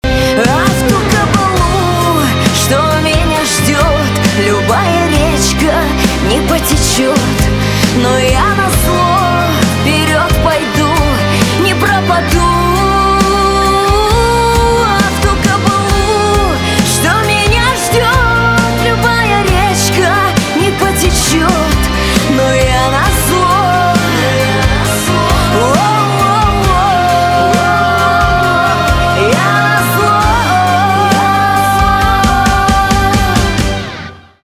Рингтоны шансон , Гитара
барабаны